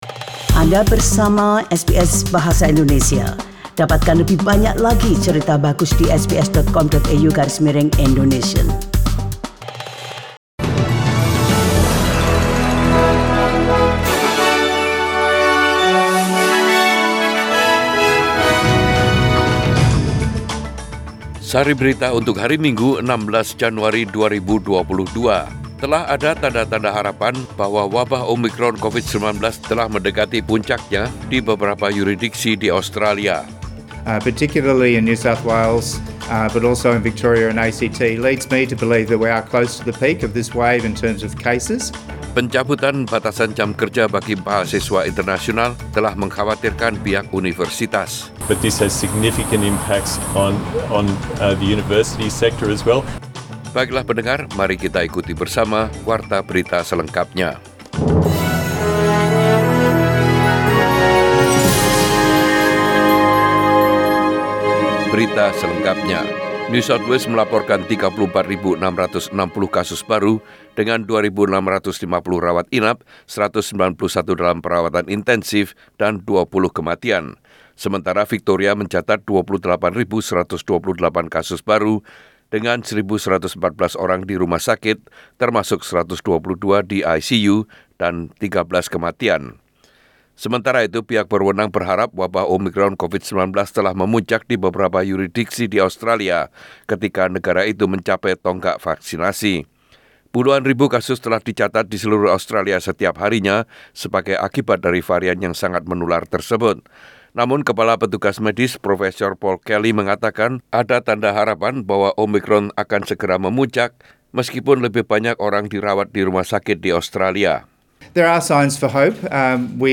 SBS Radio News in Bahasa Indonesia - 16 January 2022
Warta Berita Radio SBS Program Bahasa Indonesia.